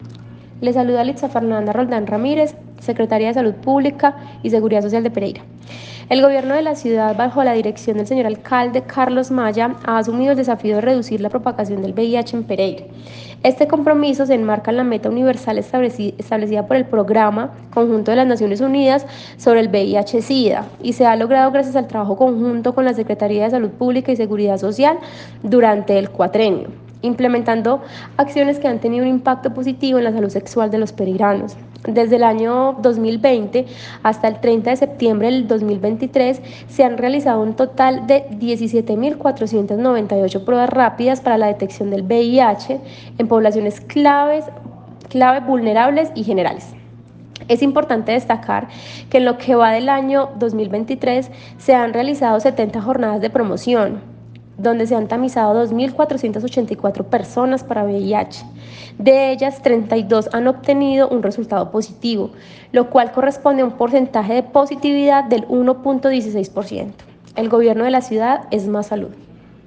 Audio_Secretaria_de_Salud_Litza_Fernanda_Roldan.ogg